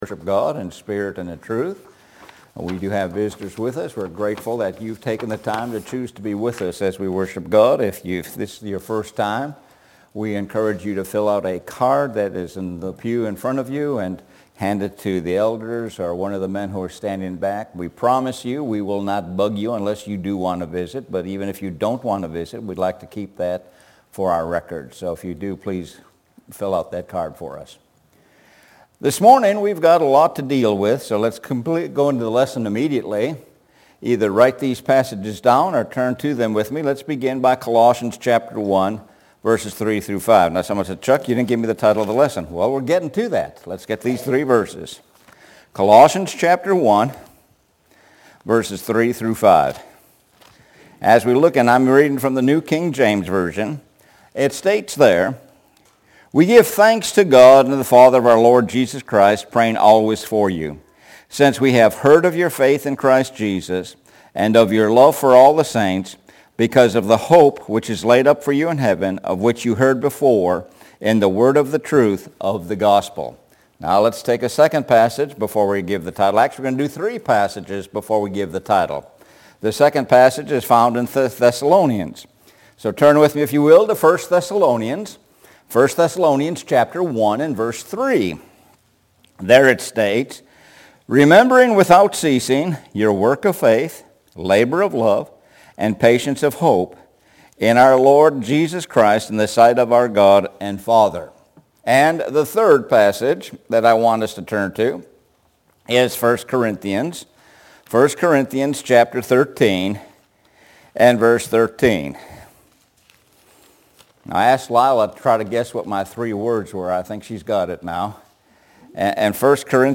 Sun AM Bible Study